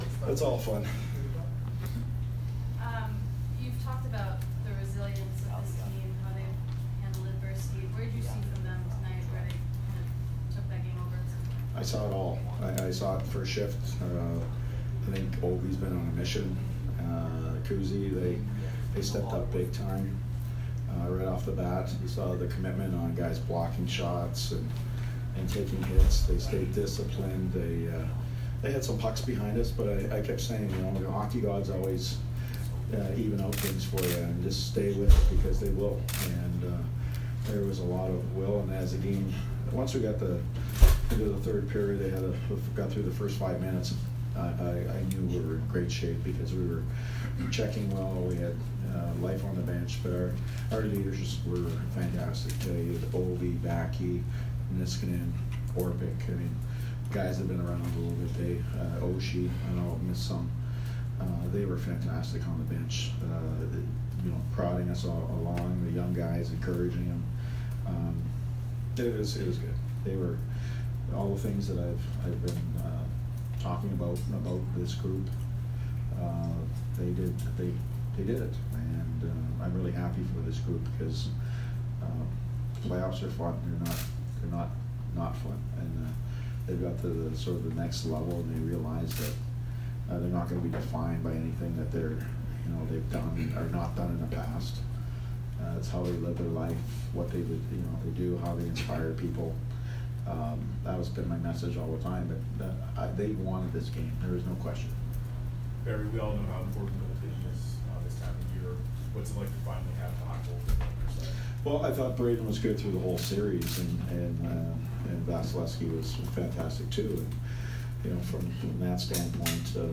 Barry Trotz post-game 5/23